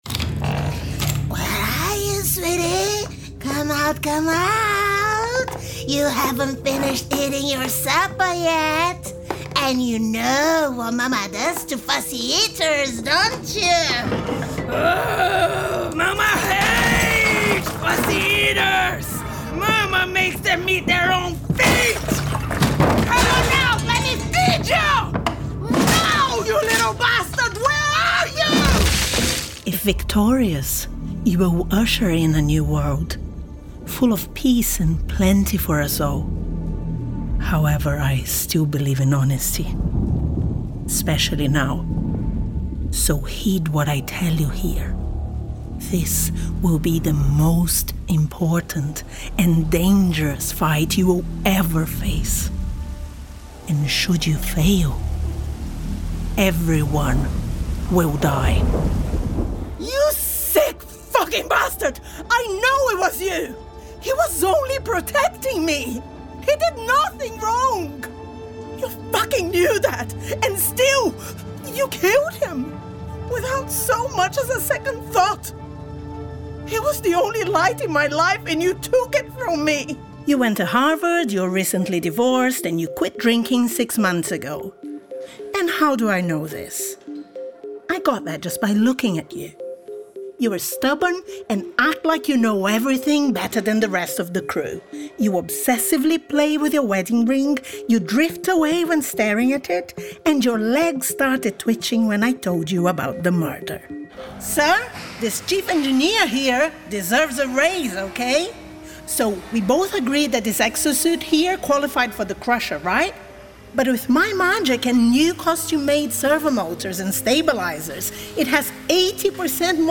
English Language Gaming Showreel
Video Game Showreel
Female
English with International Accent
Husky (light)
Smooth